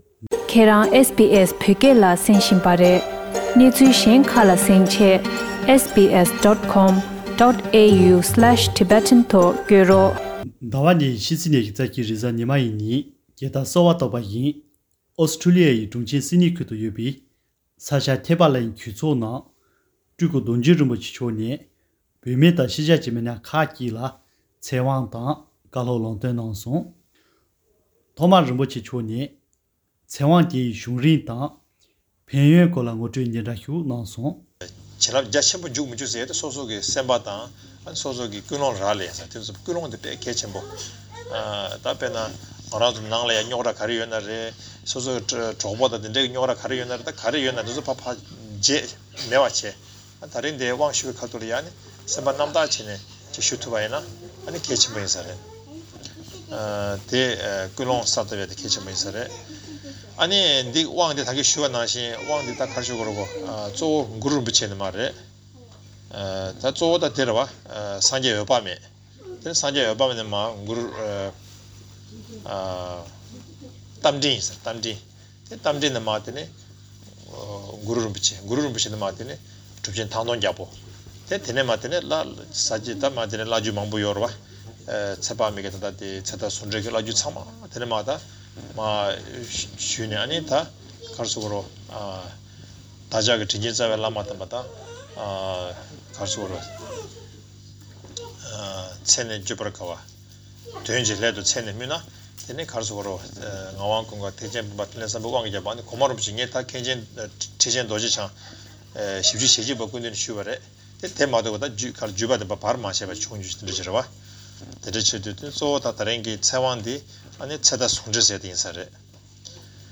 ཨོ་སི་ཊོ་ལི་ཡའི་གྲོང་ཁྱེར་སིཌ་ནིང་ཁུལ་དུ་ཡོད་པའི་ས་སྐྱ་ཐེག་པ་གླིང་ཆོས་ཚོགས་ནང་།